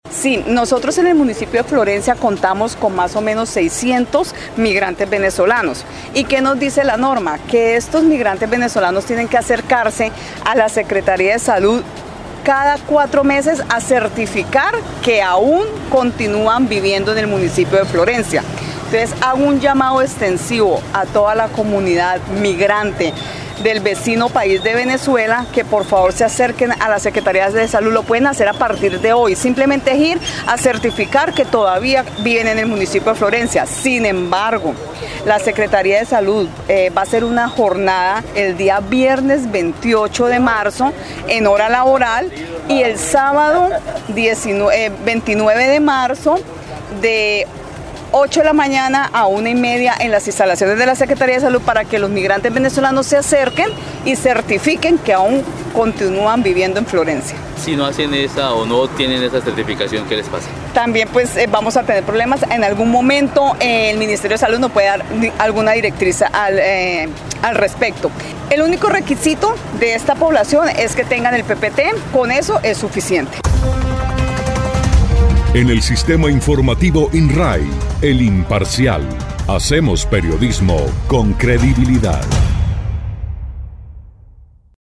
Sandra Liliana Vallejo, secretaria de salud municipal de Florencia, dijo que para que los cerca de 600 migrantes venezolanos, se realizarán dos jornadas de certificación para esta comunidad los días 28 y 29 de marzo, en las instalaciones de la secretaría.